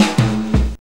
JAZZ FILL 8.wav